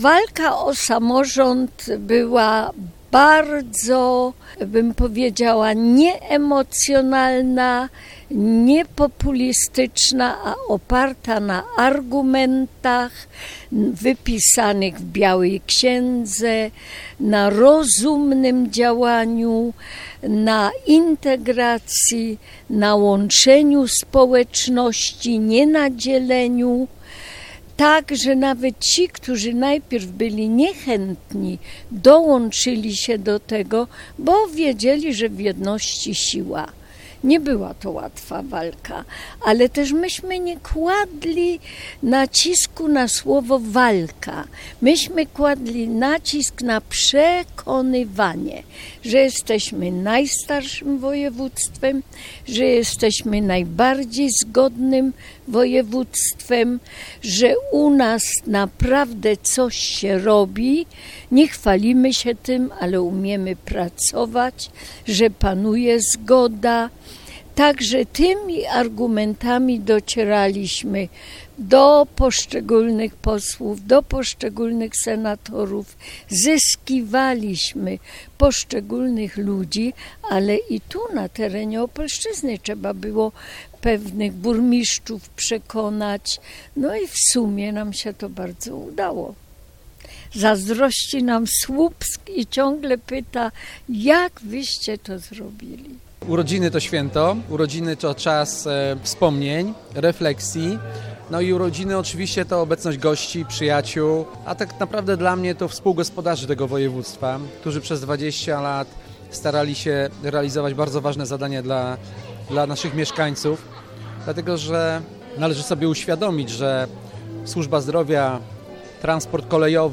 Uroczystą sesję uświetnił chór zespołu pieśni i tańca Opole.
sesja-specjalna.mp3